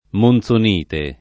monzonite [ mon Z on & te ] s. f. (min.)